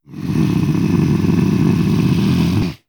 RiftMayhem / Assets / 1-Packs / Audio / Monster Roars / 13.
13. Tentative Growl.wav